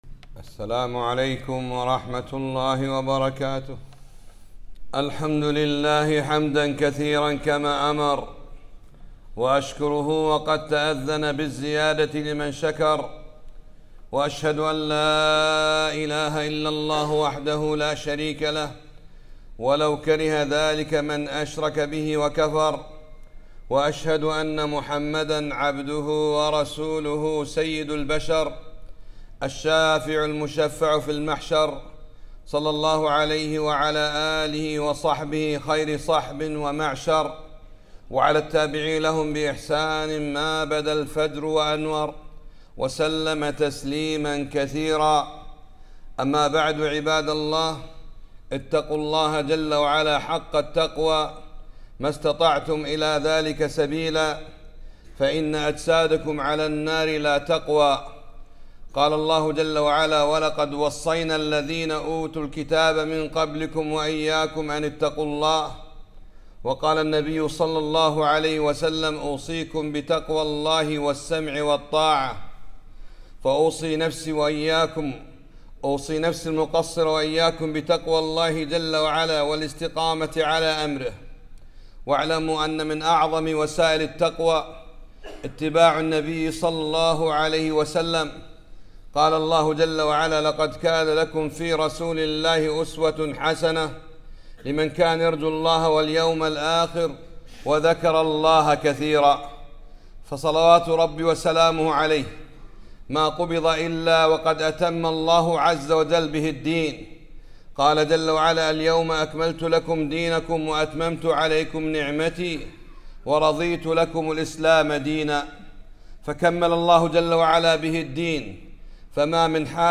خطبة - وما النصرُ إلا من عند الله